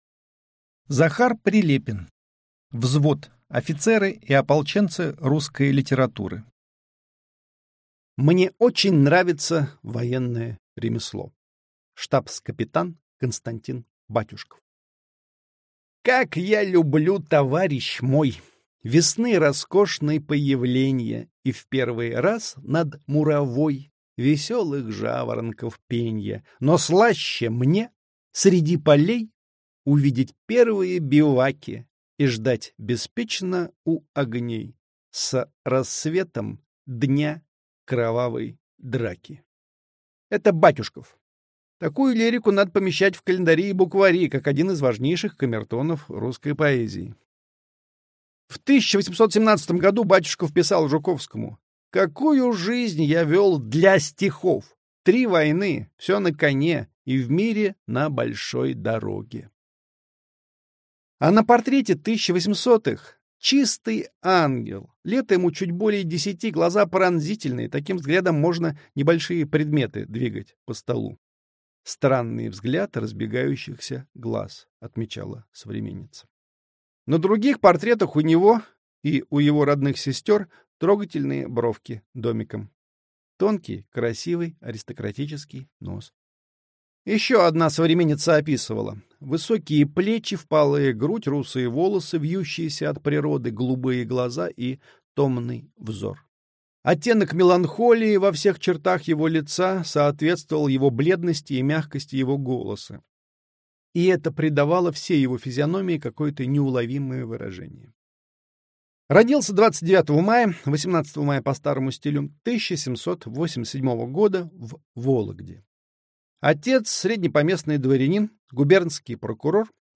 Аудиокнига Взвод. Офицеры и ополченцы русской литературы. Штабс-капитан Константин Батюшков | Библиотека аудиокниг